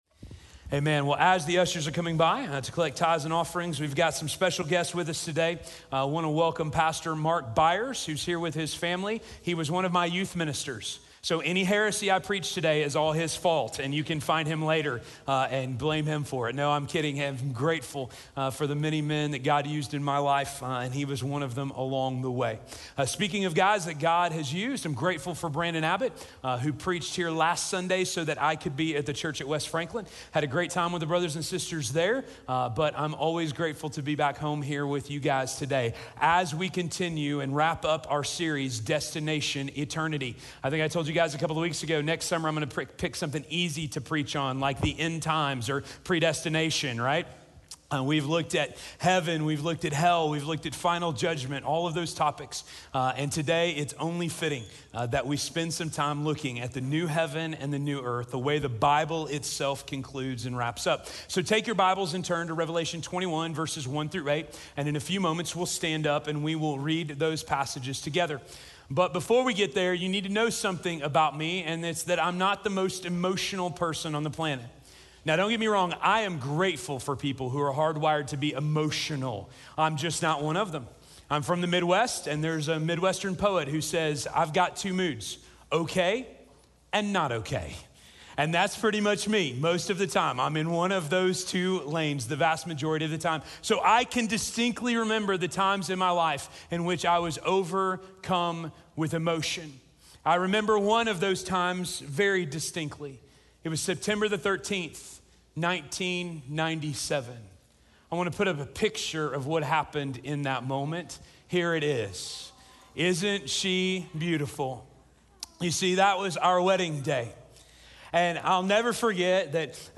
The New Heaven and the New Earth - Sermon - Station Hill